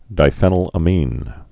(dī-fĕnəl-ə-mēn, -ămĭn, -fēnəl-)